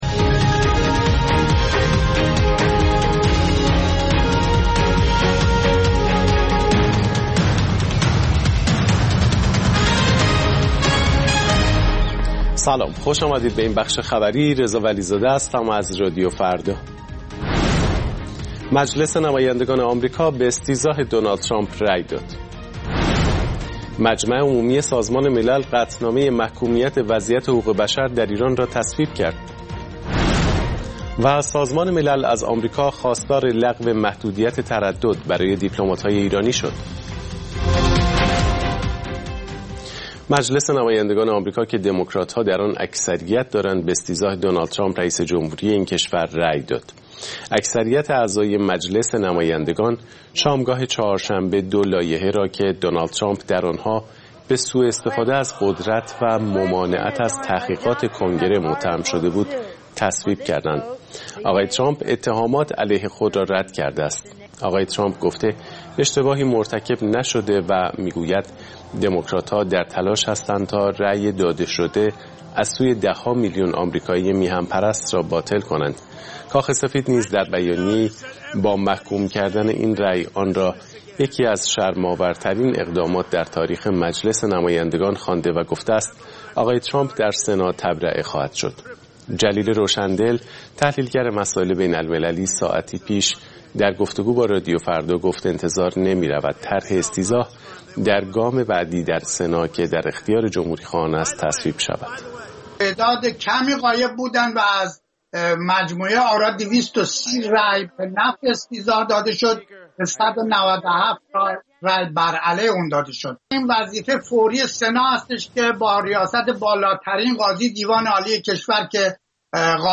اخبار رادیو فردا، ساعت ۱۲:۰۰